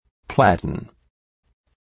Προφορά
{‘plætən}